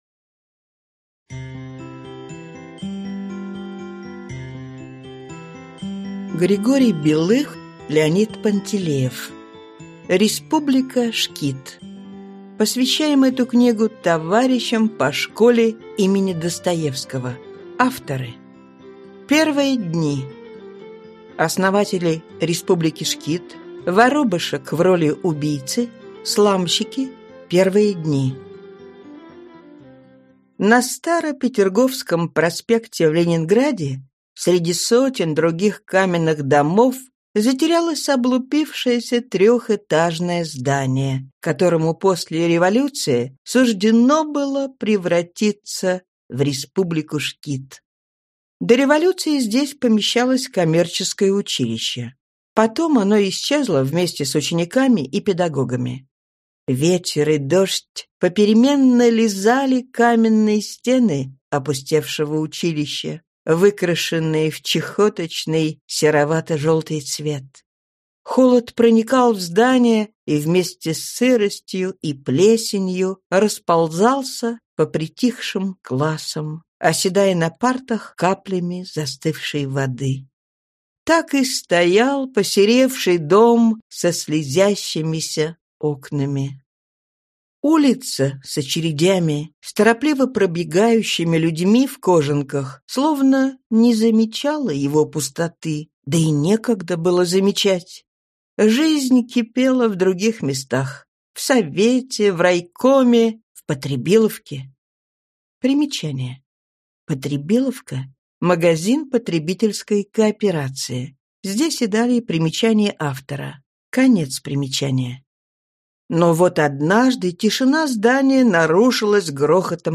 Аудиокнига Республика ШКИД | Библиотека аудиокниг